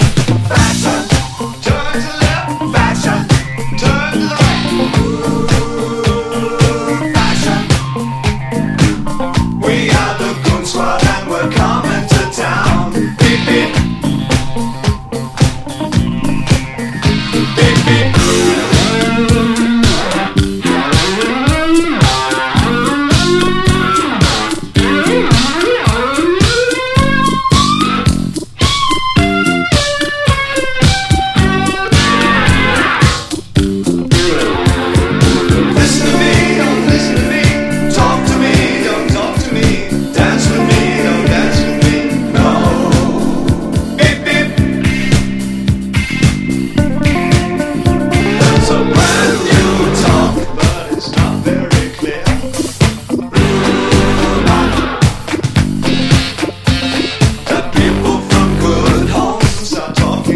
ROCK / 80'S/NEW WAVE. / NEW WAVE / PUB ROCK / NEW WAVE DISCO
超ファンキー・パブ・ディスコ！を集めたベスト・アルバム！